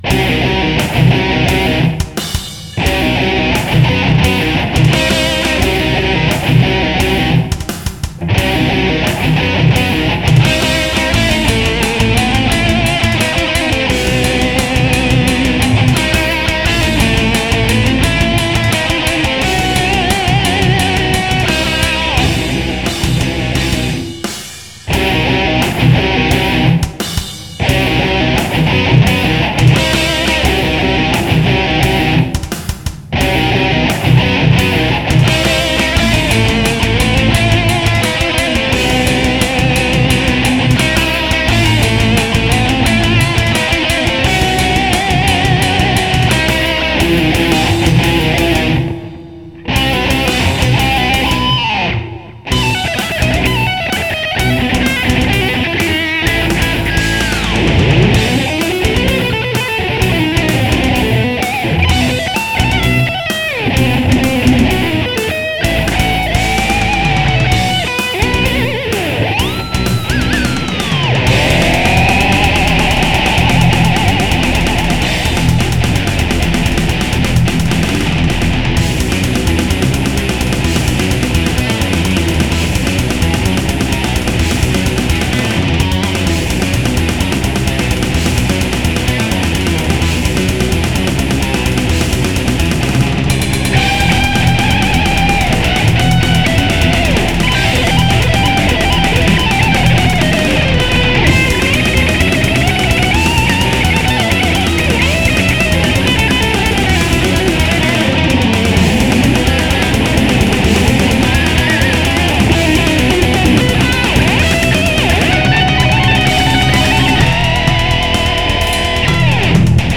Vos Compos Metal
Toujours pas de chant à l'horizon !
Lyrique et mystique, son :bravo:
Non pas trop long parce que diversifié :bravo: choix des synthés :bravo: mais écoute "sex and religion" tu vas te "retrouver" te reconnaître, en plus, c'est devin townsend qui chante, et il semble que ce type aie un public... depuis :clin: